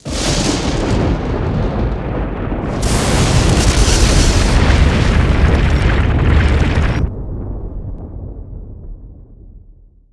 Âm thanh tiếng mặt đất Nứt Nẻ và tiếng Sét
Thể loại: Tiếng thiên nhiên
Description: Bạn đang nghe là sound effects tiếng mặt đất nứt nẻ và tiếng sấm sét, có thể mô tả âm thanh của động đất, âm thanh nổi giận hung dữ của thiên nhiên, âm thanh báo hiệu thiên tai đang xảy ra, gây ra mối đe dọa lớn cho loài người.
Tieng-mat-dat-nut-ne-va-tieng-set-www-tiengdong_com.mp3